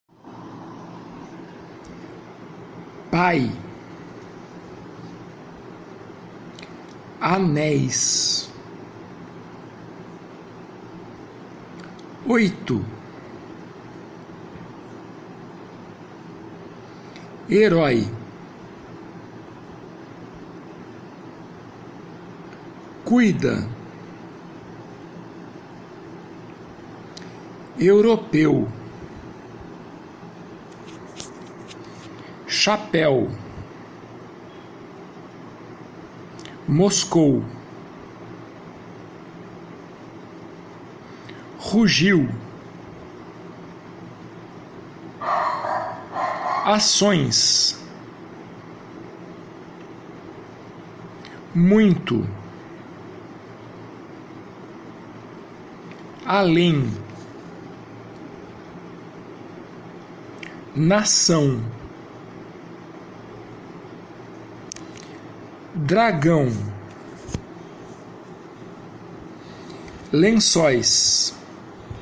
Faça download dos arquivos de áudio e ouça a pronúncia das palavras a seguir para transcrevê-las foneticamente.
GRUPO 1 - Ditongos - Arquivo de áudio -->